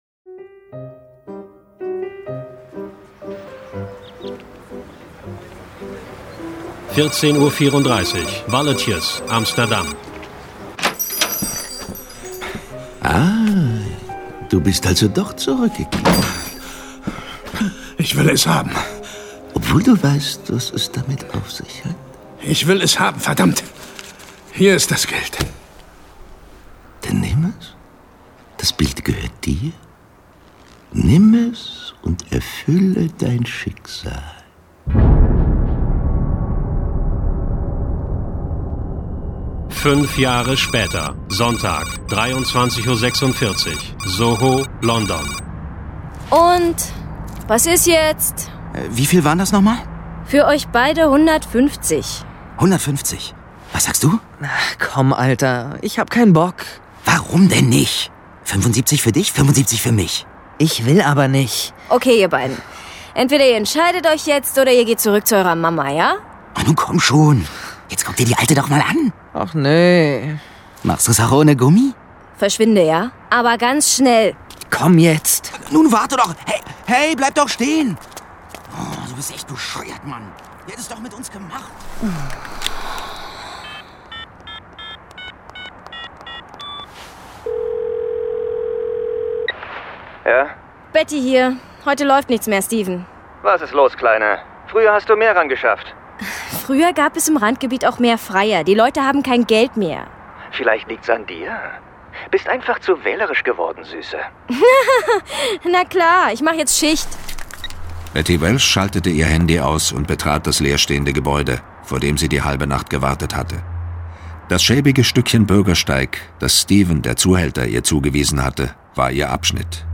John Sinclair - Folge 49 Ich jagte Jack the Ripper. Hörspiel.